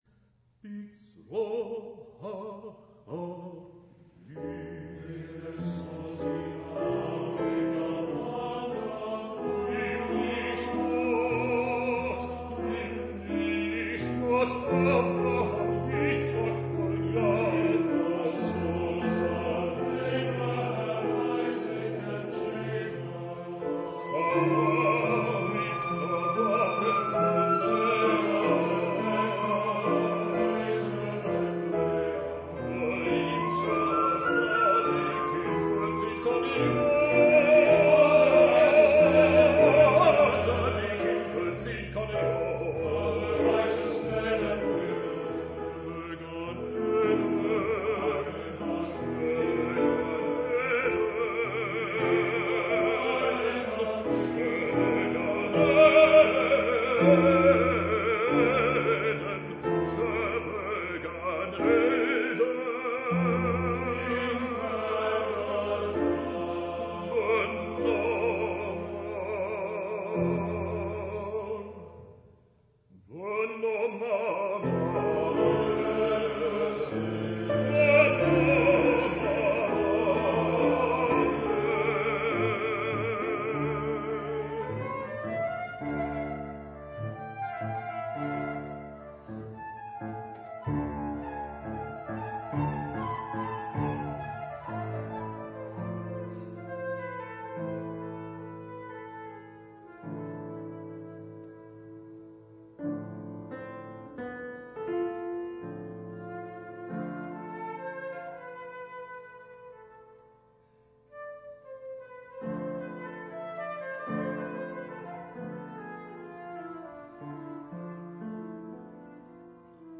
canciones para barítono y piano